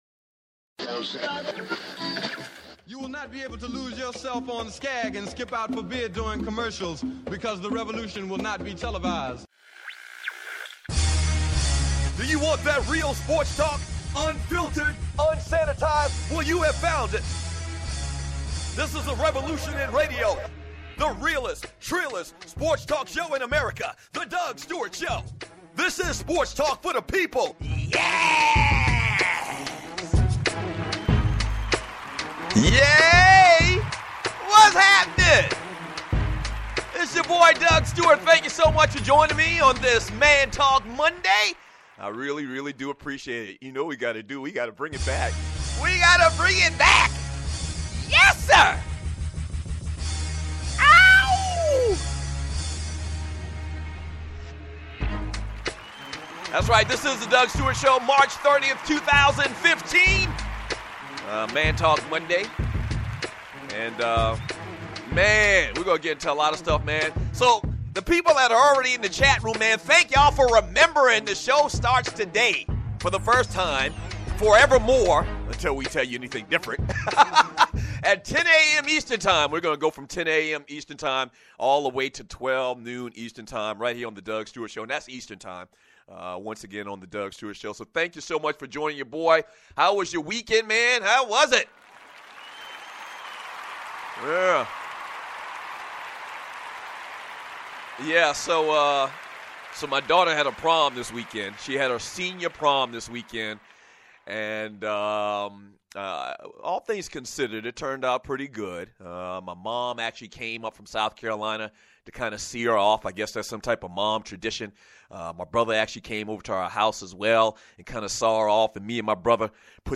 the most different, most high energy and fun sports talk show in the world!